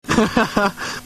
The Laugh